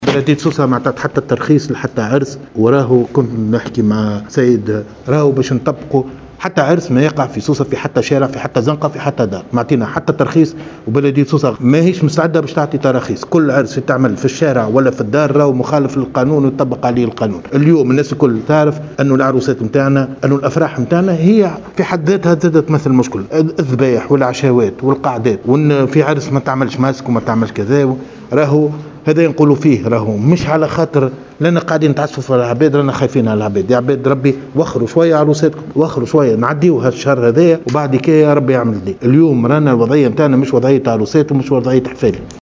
وبيّن خالد خلال ندوة صحفية عُقدت بمقر ولاية سوسة، أنّ هذا القرار يأتي خوفا على المواطنين، داعيا إياهم إلى تأجيل مناسبتهم، مضيفا بالقول"كل الأعراس في الشارع وِلاّ في الدار مخالف ويطبّق عليه القانون.. يا عِباد ربي وخّروا عروساتكم.. الوضعية مش متاع عروسات وحفالي"، حسب تعبيره.